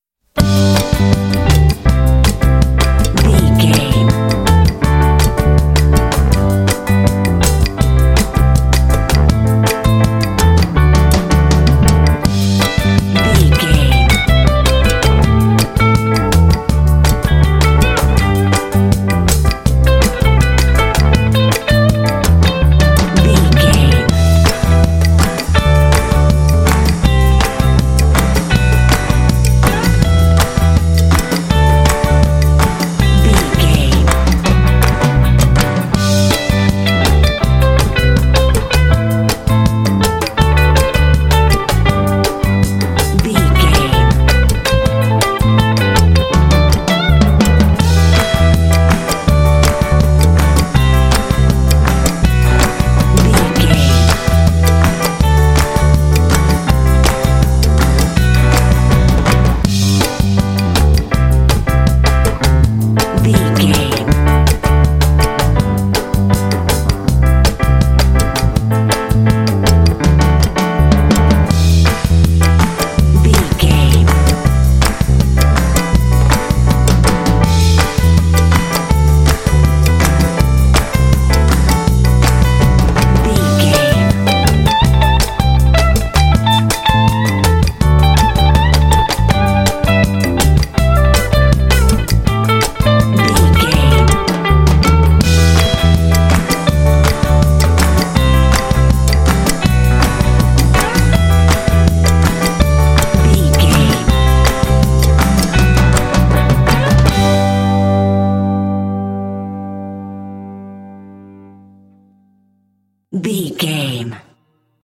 This fun and upbeat track has an energetic Latin groove.
Uplifting
Ionian/Major
joyful
groovy
driving
latin jazz